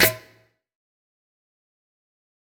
SouthSide Snare Roll Pattern (27).wav